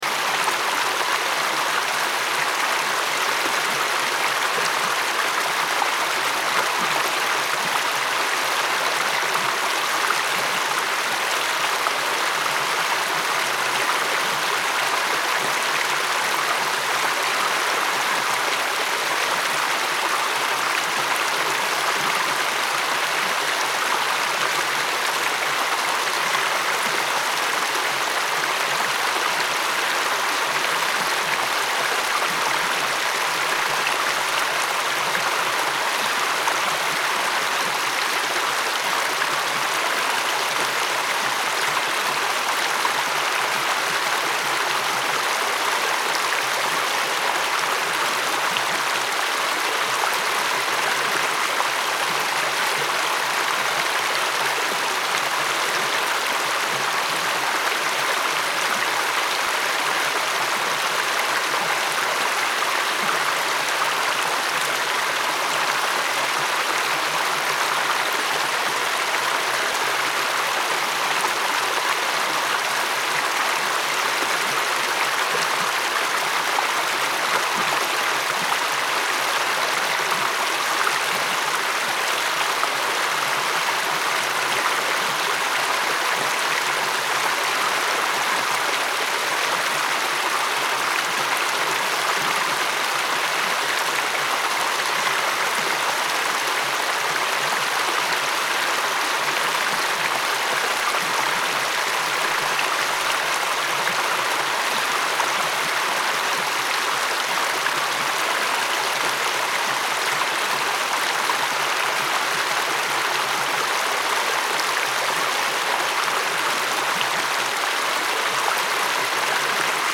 Running Water Sound For Sleep – Relaxing Stream Ambience
Listen to relaxing running water sounds from a peaceful natural stream.
Enjoy gentle water flow, soft stream ambience, and natural white noise for deep sleep and daily calm.
Genres: Sound Effects
Running-water-sound-for-sleep-relaxing-stream-ambience.mp3